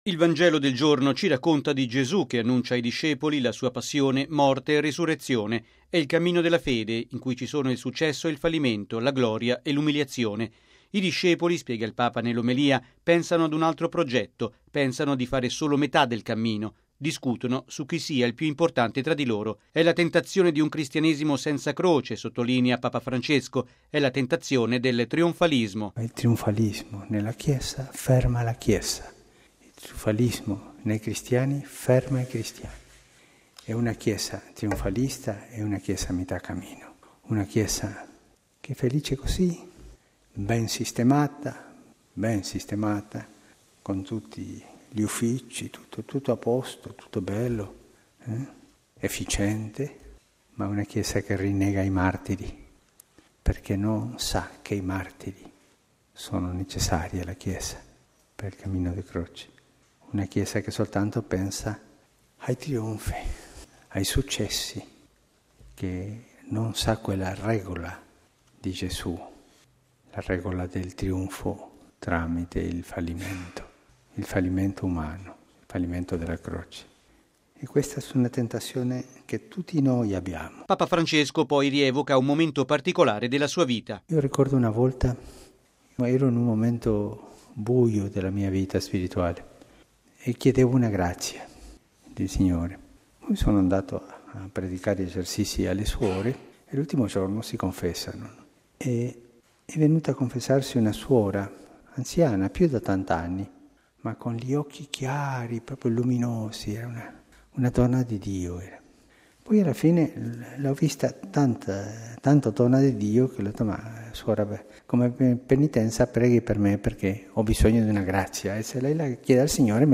◊   Il trionfalismo ferma la Chiesa: è la tentazione del cristianesimo senza Croce, la Chiesa sia invece umile. E’ quanto ha affermato il Papa stamani nella Messa a “Santa Marta”.